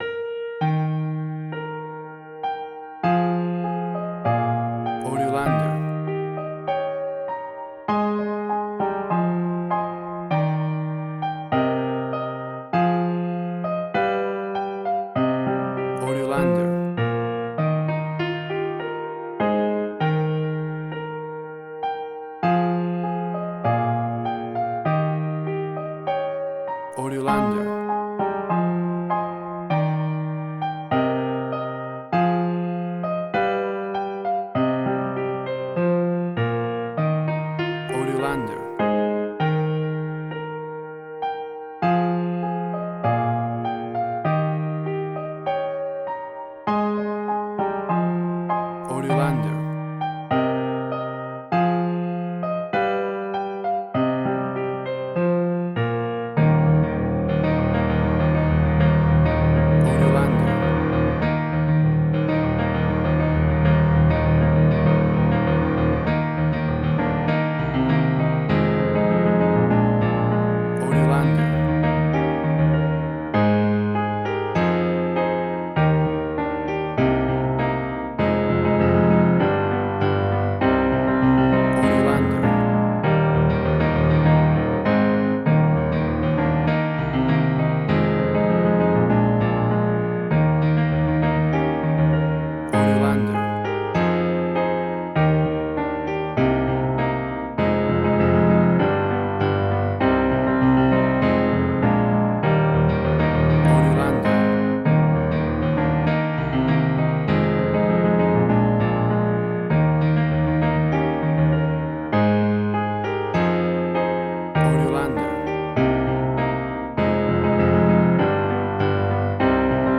A traditional harp rendition
Christmas music
Full of happy joyful festive sounds and holiday feeling!.
WAV Sample Rate: 16-Bit stereo, 44.1 kHz
Tempo (BPM): 99